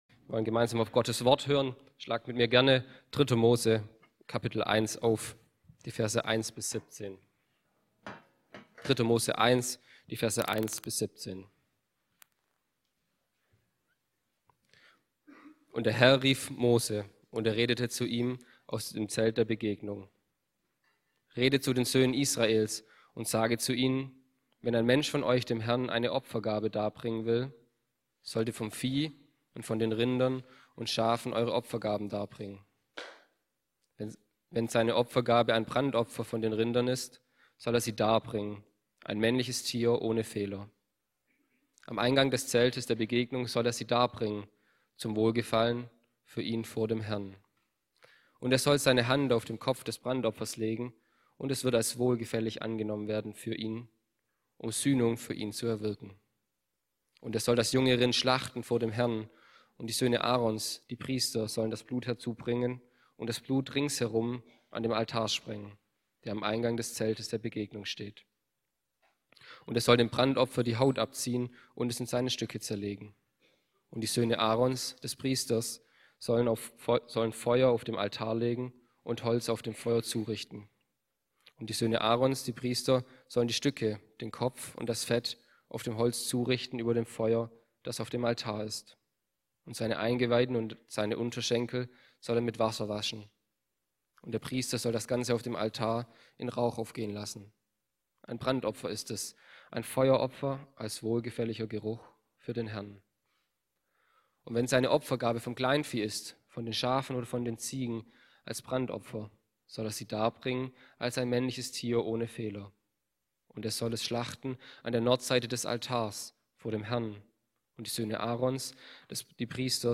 Serie: Einzelne Predigten